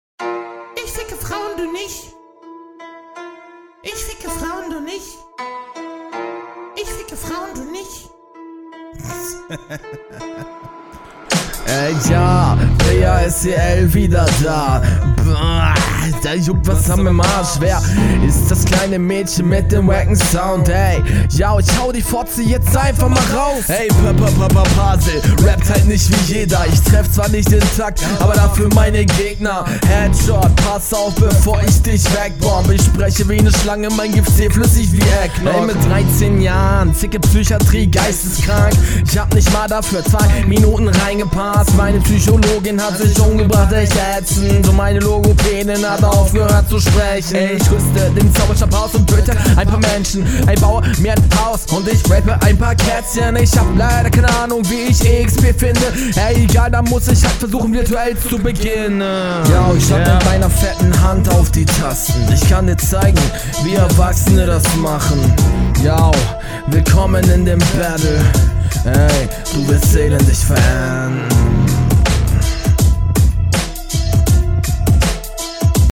Flow: Geile Stimme aber sonst auch schwierig Text: WTF Was soll das sein?
Flow: Flow ansprechende Variationen.